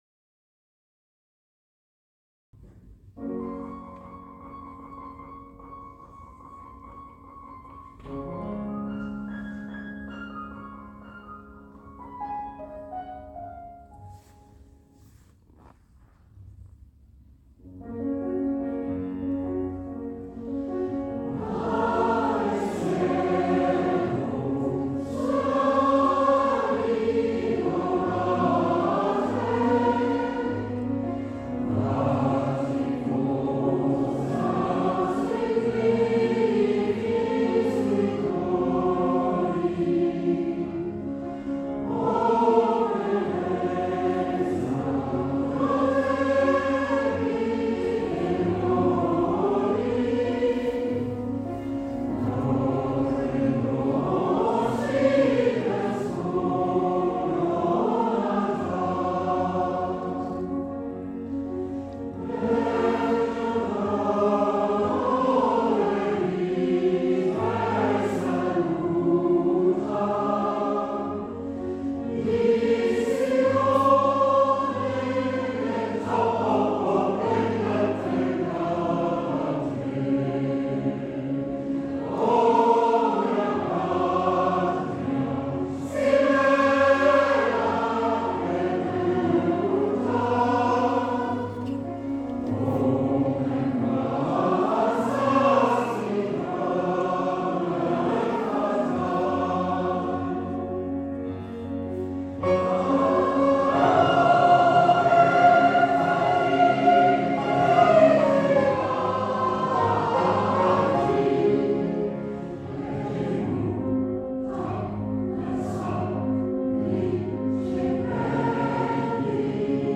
The following audio recordings are snippets from previous concerts to give you a taste of our repertoire